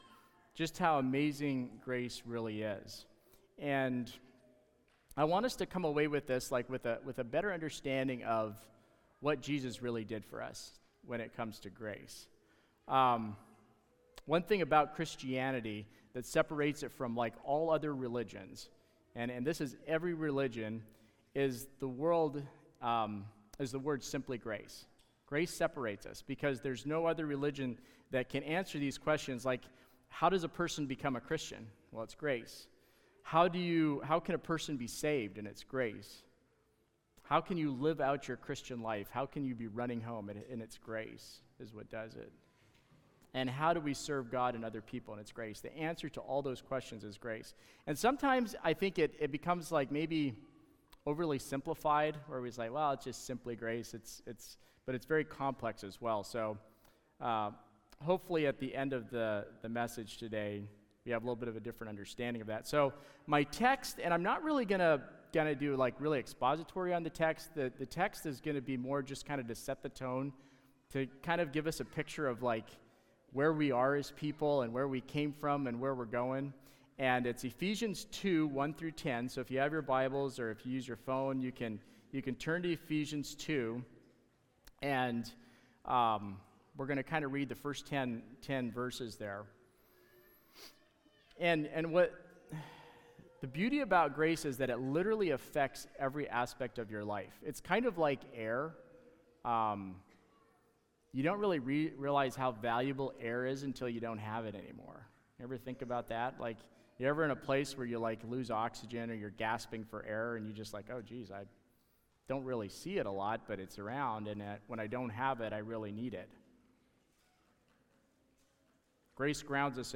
Sermons | Christian Life Mennonite